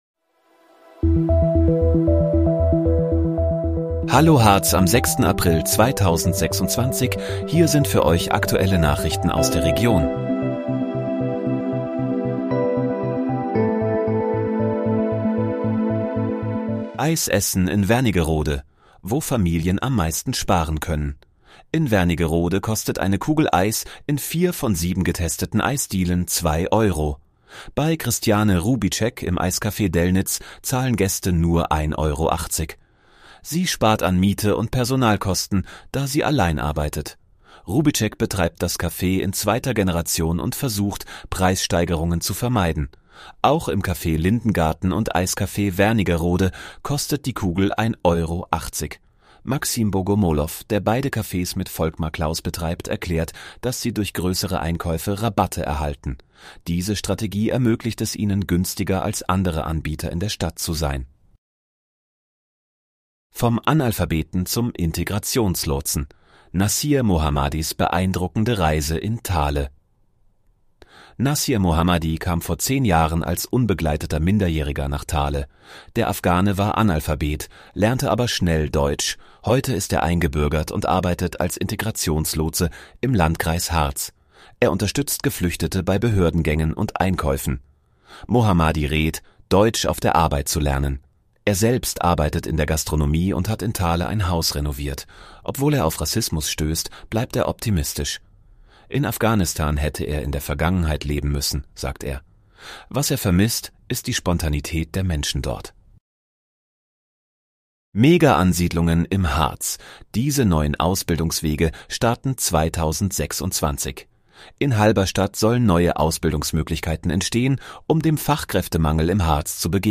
Hallo, Harz: Aktuelle Nachrichten vom 06.04.2026, erstellt mit KI-Unterstützung